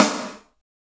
Index of /90_sSampleCDs/Roland - Rhythm Section/KIT_Drum Kits 8/KIT_Cracker Kit
SNR PICC S02.wav